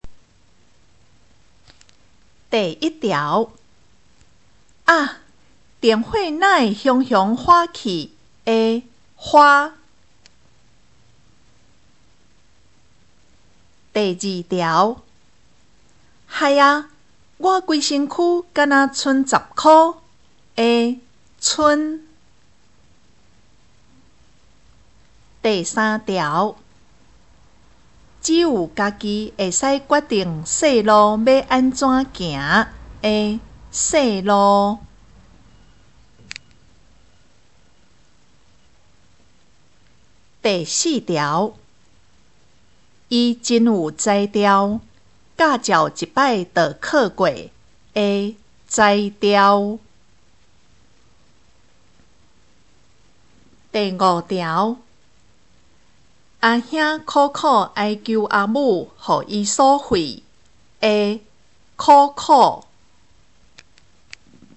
【國中閩南語4】每課評量(1)聽力測驗mp3